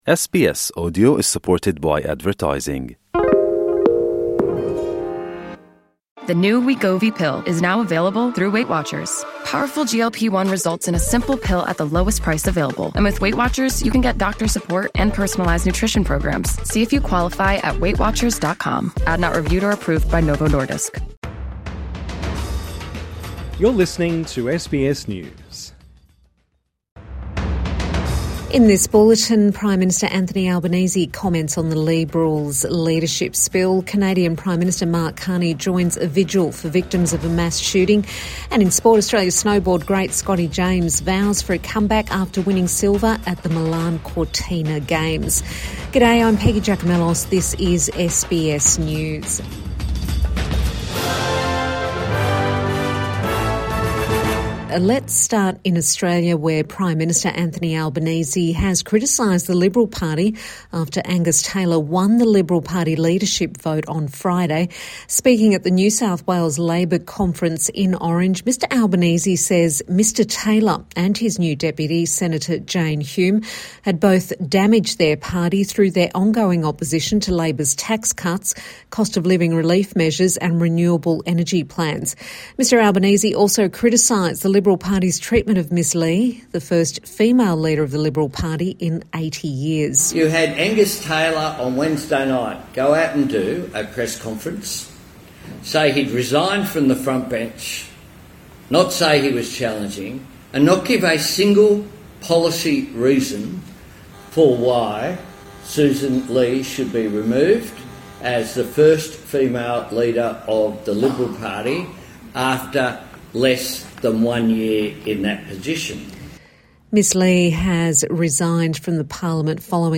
Anthony Albanese comments on the Liberals leadership spill | Evening News Bulletin 14 February 2026